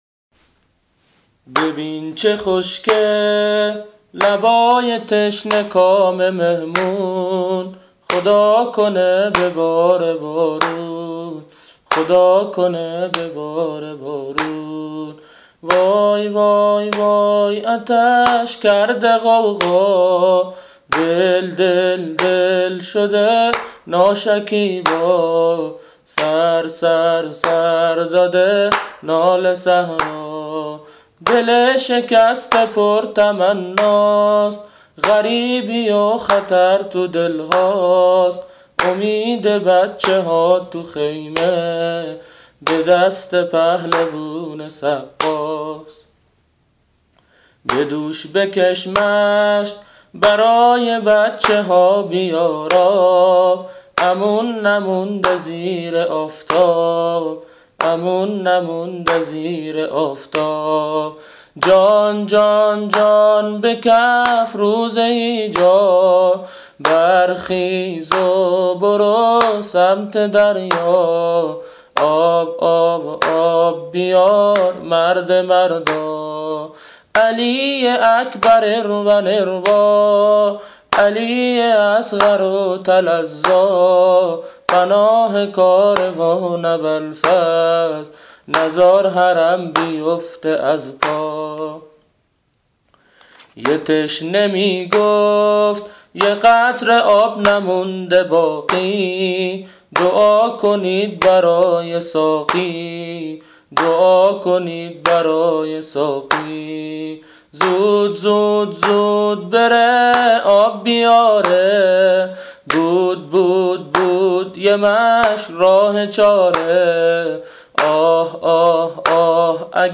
مداحی
دو شعر کارگر را در سبک زمینه حضرت ابوالفضل